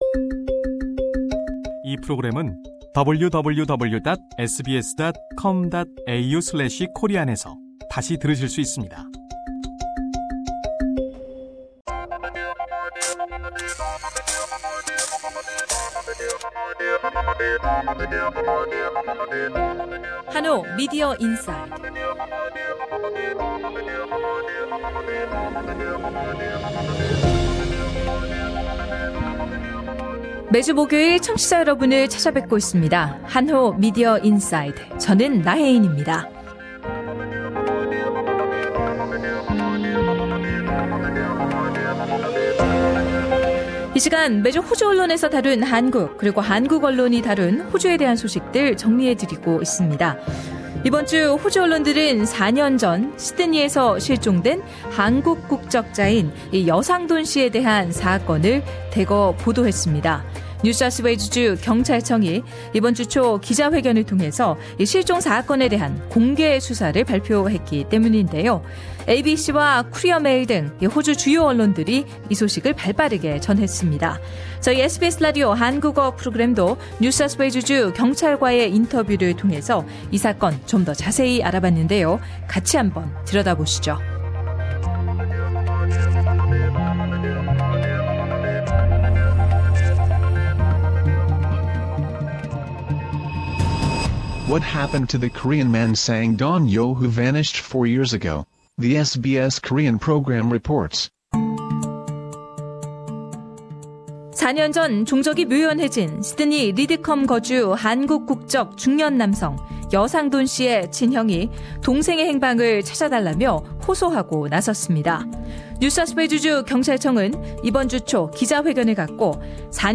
exclusive interview